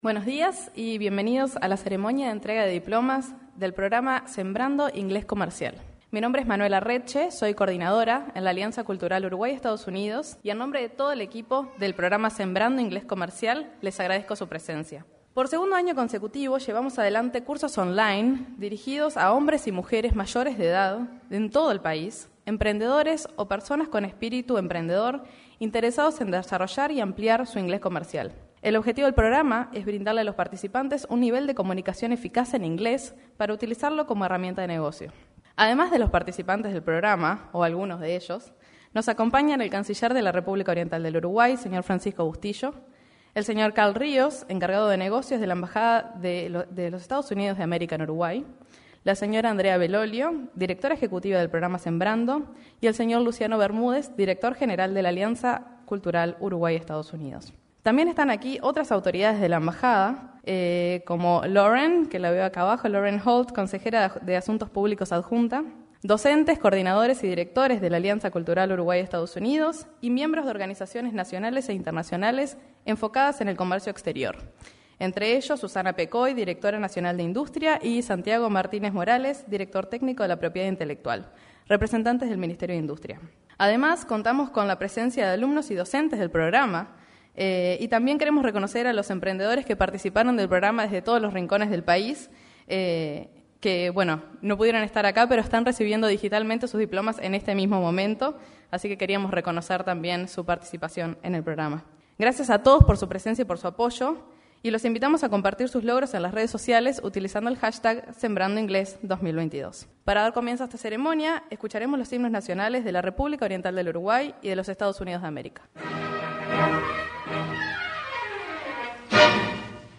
Entrega de diplomas del programa Sembrando Inglés Comercial 22/11/2022 Compartir Facebook X Copiar enlace WhatsApp LinkedIn En el auditorio del anexo de la Torre Ejecutiva, se realizo la entrega de diplomas del programa Sembrando Inglés Comercial.